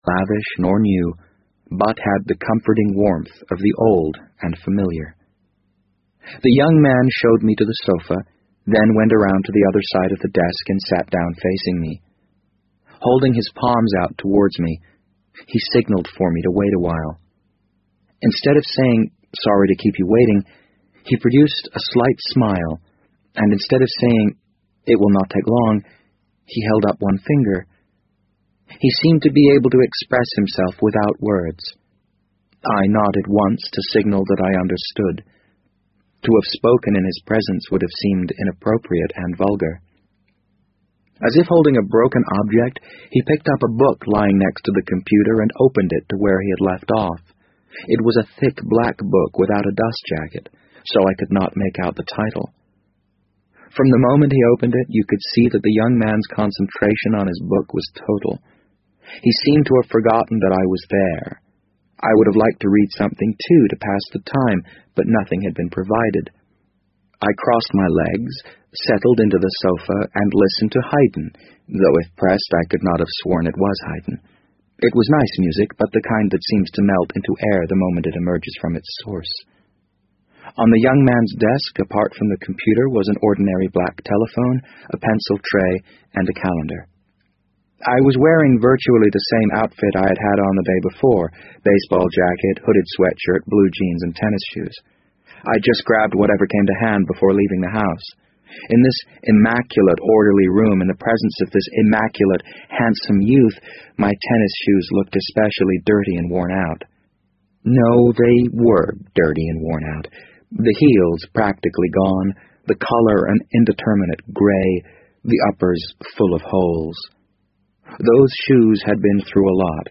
BBC英文广播剧在线听 The Wind Up Bird 009 - 17 听力文件下载—在线英语听力室